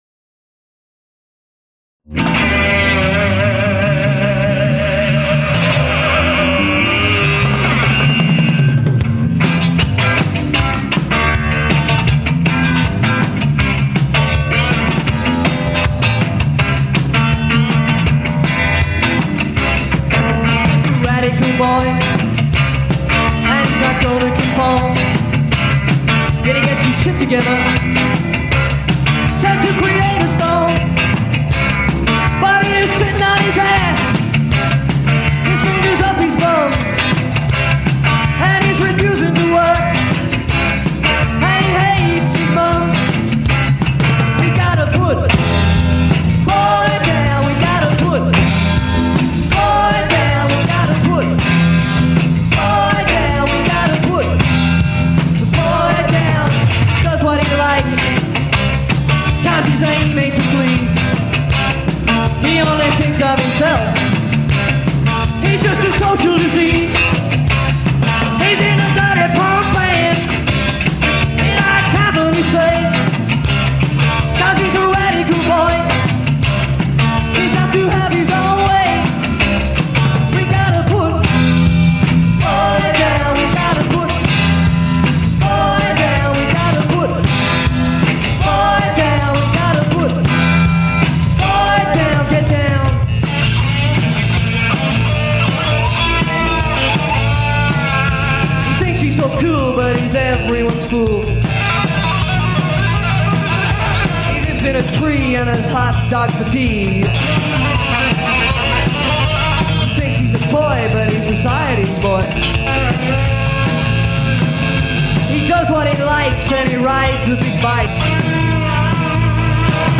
lead guitar/vocals
drums/sound
live